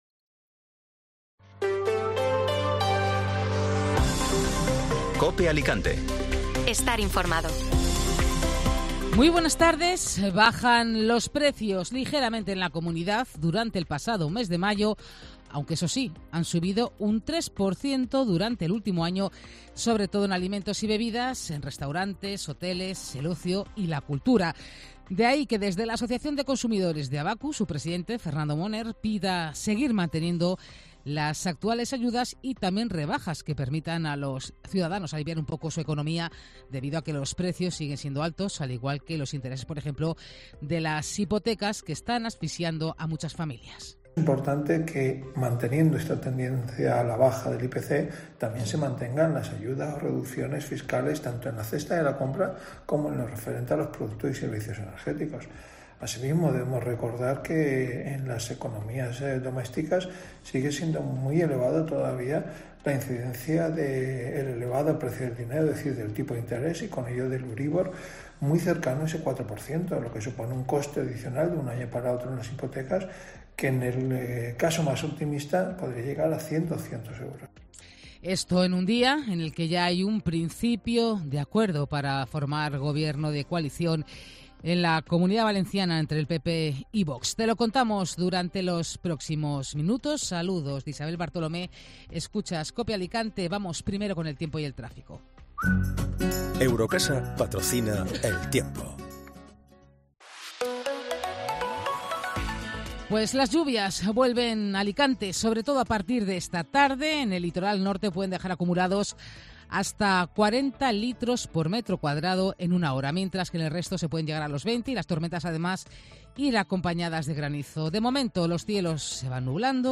Informativo Mediodía Cope Alicante ( Martes 13 de junio)